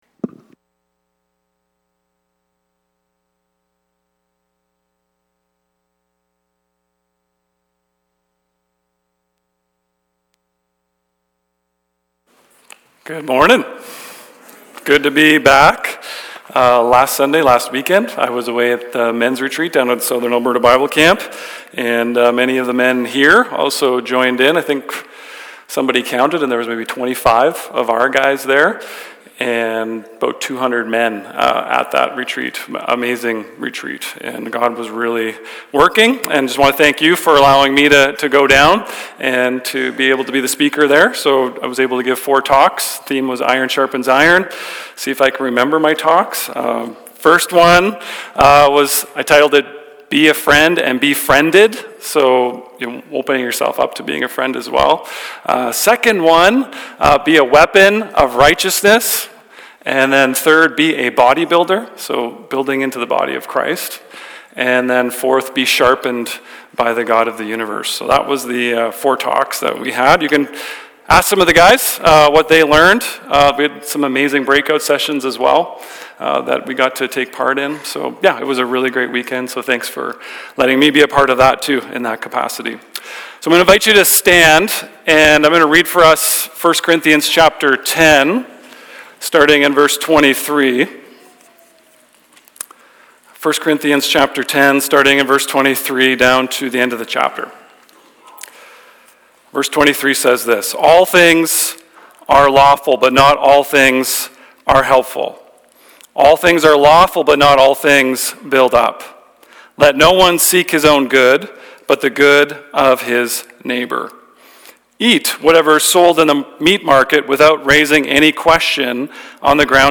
Sermons | Brooks Evangelical Free Church